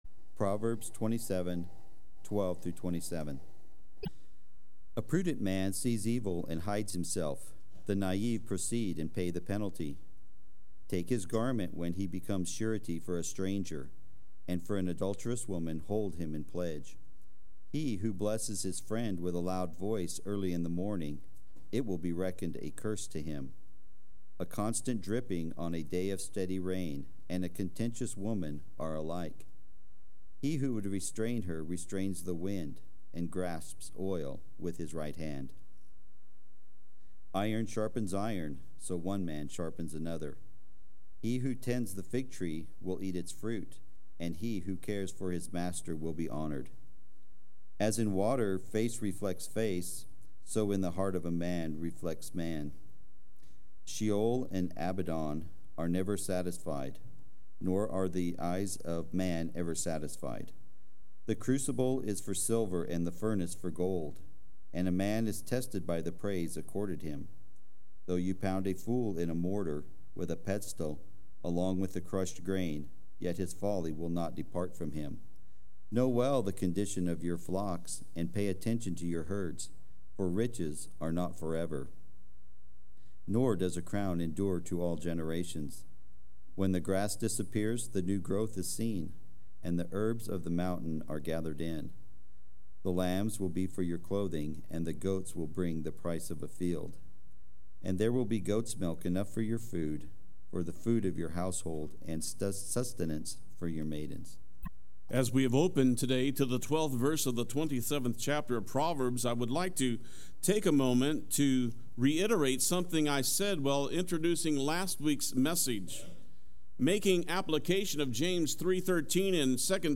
Play Sermon Get HCF Teaching Automatically.
“Iron Sharpens Iron” Part II Sunday Worship